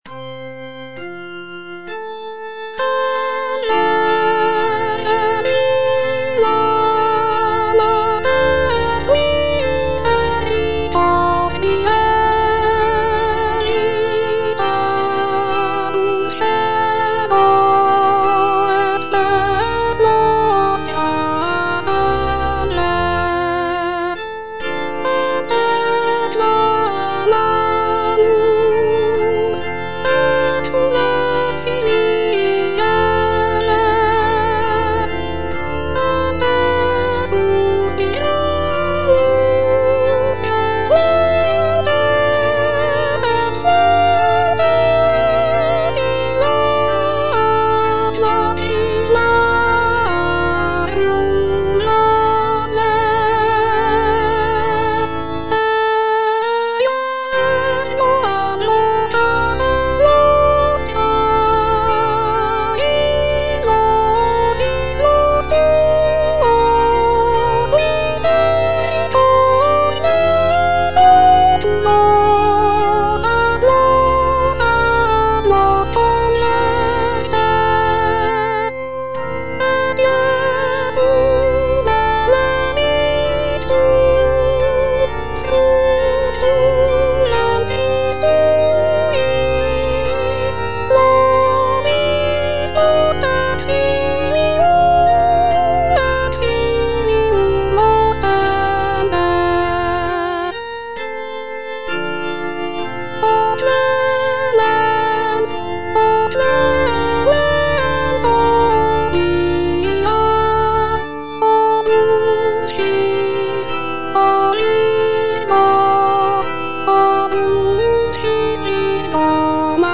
Soprano (Voice with metronome